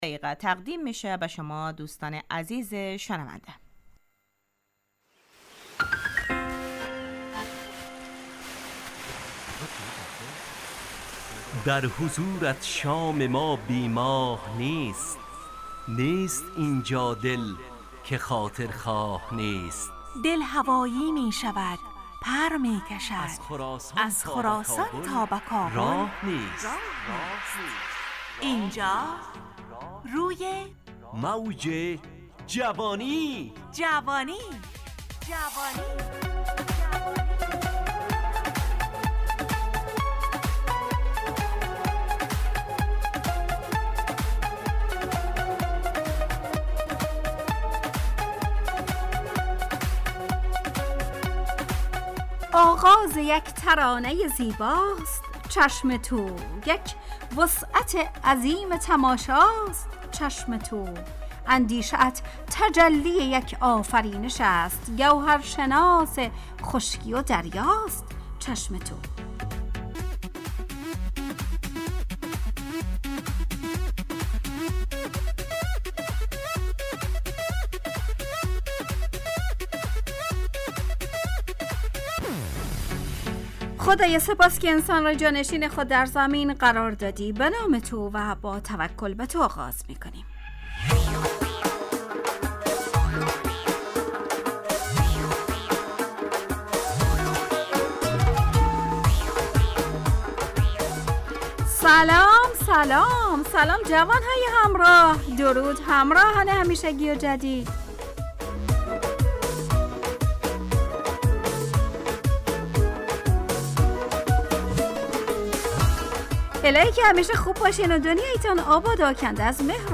روی موج جوانی برنامه ی شاد و عصرانه از رادیو دری از شنبه تا پنجشنبه ازساعت 4:45 الی5:55 به وقت افغانستان با طرح موضوعات روز وآگاهی دهی برای جوانان، و.....